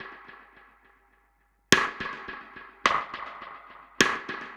Index of /musicradar/dub-drums-samples/105bpm
Db_DrumKitC_EchoSnare_105-01.wav